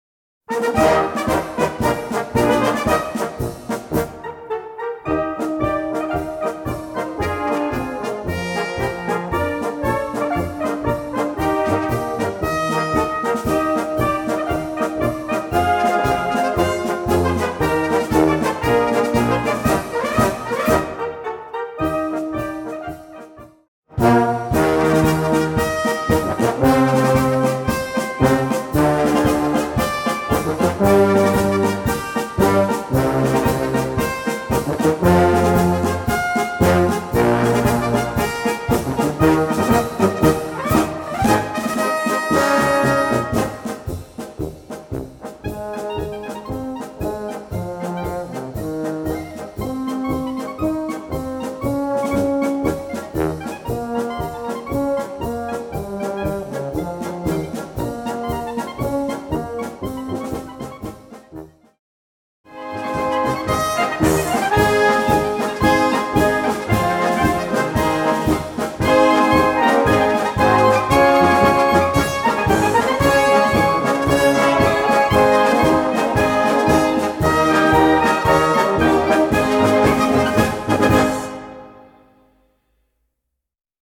der Flöte und Es-Klarinette sehr fordert